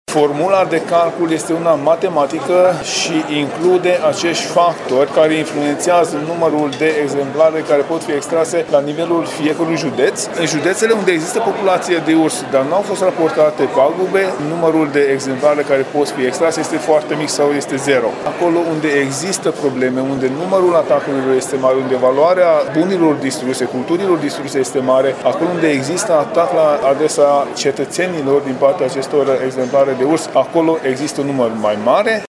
Ministrul Tanczos Barna a explicat pentru Radio Timișoara că autorizațiile de vânare vor fi emise pe zone, în funcție de numărul populației de urși și de pagubele produse: